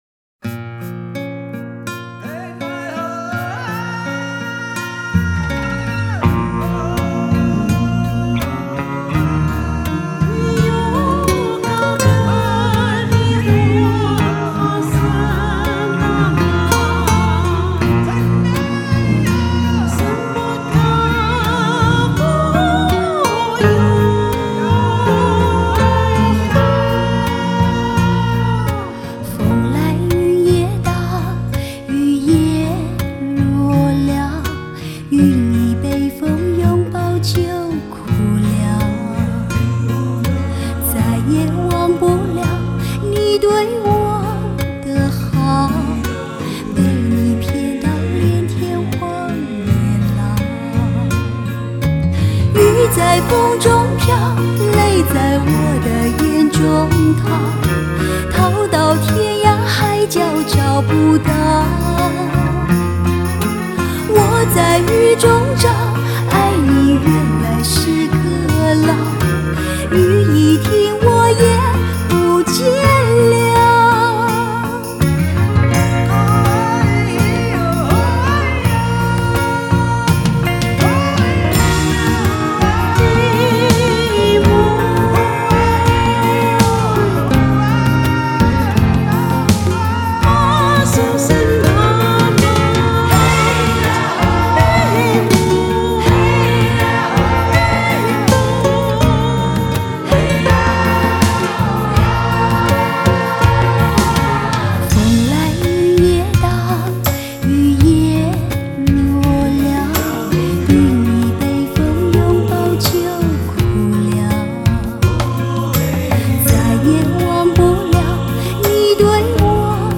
经过重新排版编曲，展现给人们另一种山地歌曲的风格。